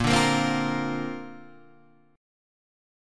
A#add9 chord {6 5 8 5 6 6} chord